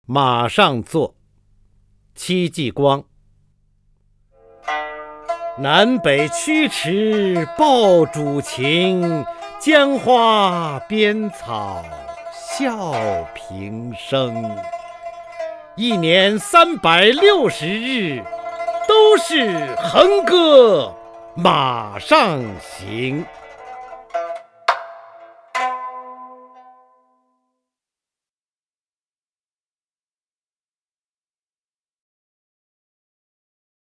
方明朗诵：《马上作》(（明）戚继光) （明）戚继光 名家朗诵欣赏方明 语文PLUS